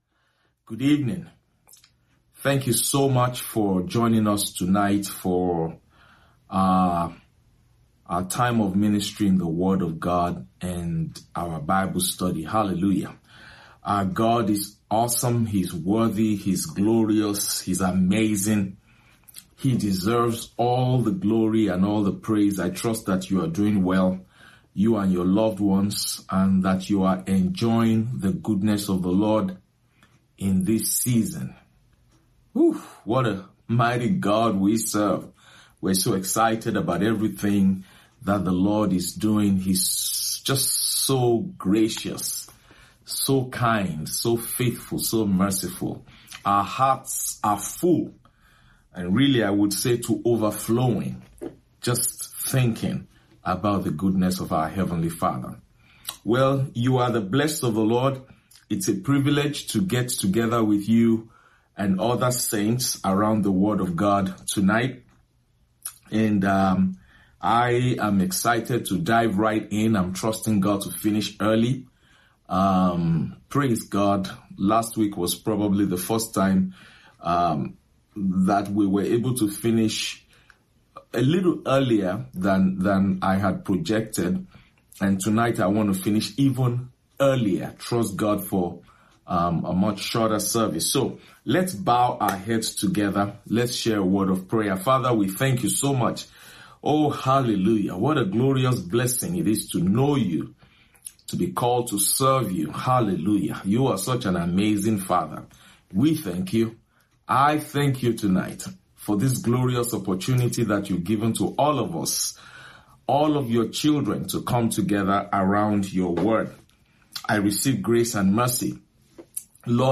Midweek Service